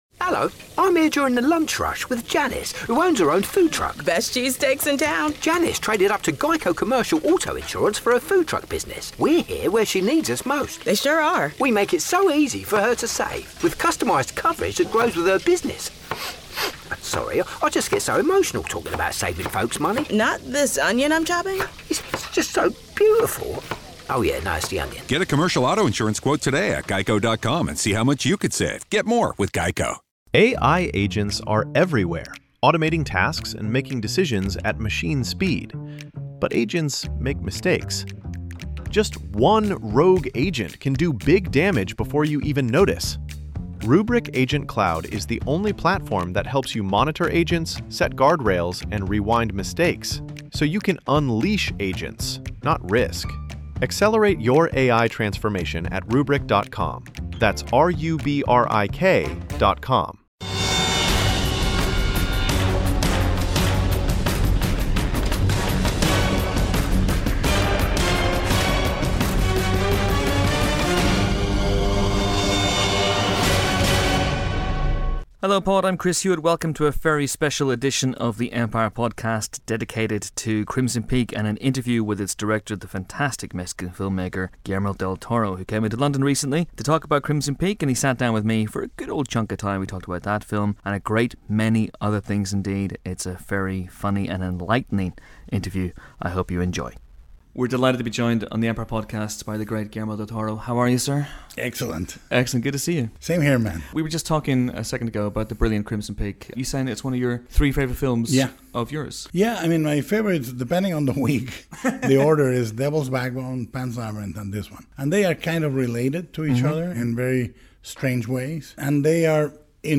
Stopping by the podcast booth to celebrate the release of new gothic horror Crimson Peak, del Toro let on that his latest film already belongs in his personally-directed top three. He also gave us the lowdown on the surprising autobiographical elements to the Tom Hiddleston-starrer, the latest on Pacific Rim 2 and his mission to spread the love on Twitter.